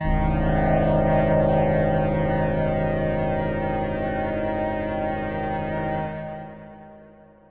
Ambient
SYNTHPAD088_AMBNT_160_C_SC3.wav
1 channel